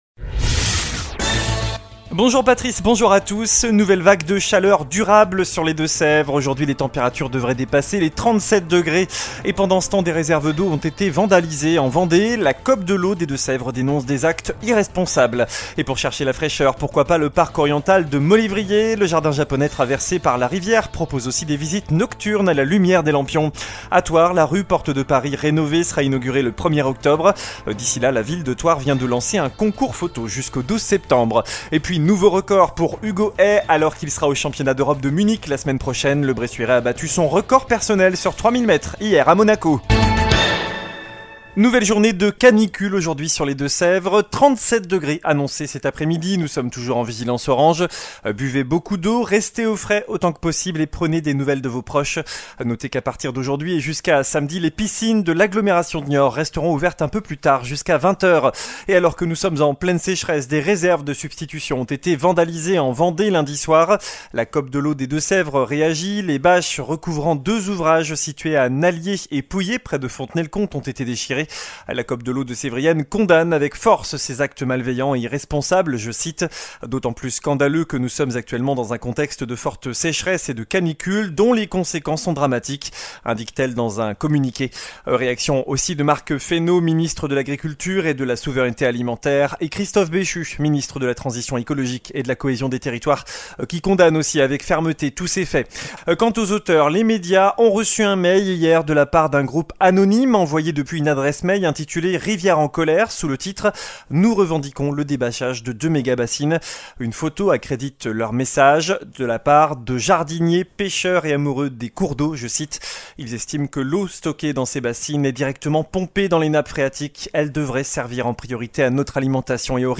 JOURNAL DU JEUDI 11 AOÛT